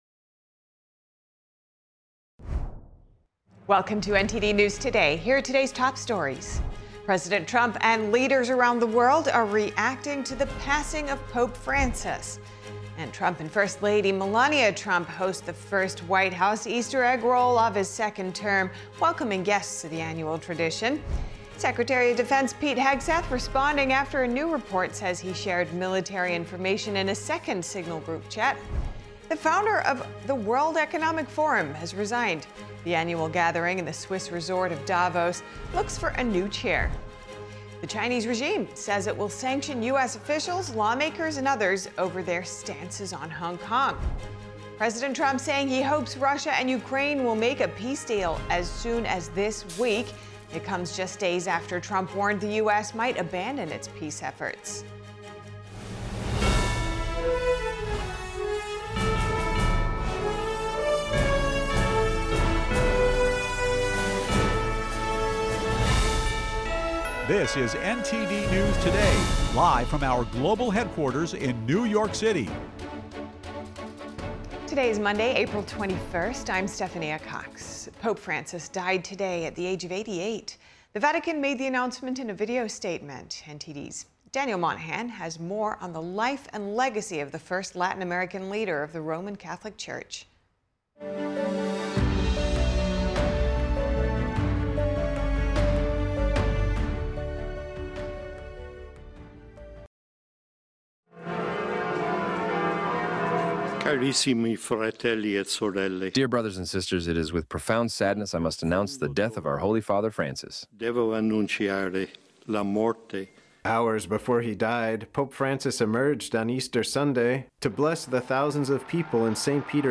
NTD-News-Today-Full-Broadcast-April-21-audio-converted.mp3